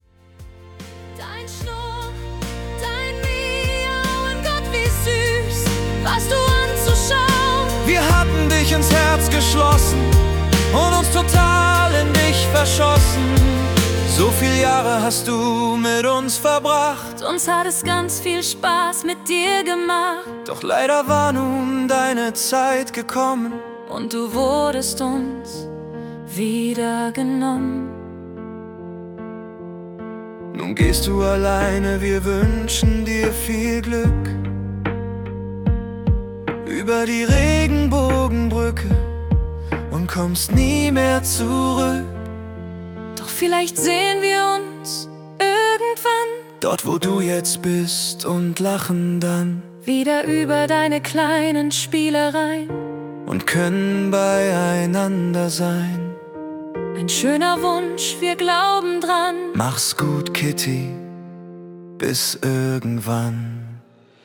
Songwriter-Ballade